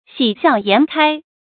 xǐ xiào yán kāi
喜笑颜开发音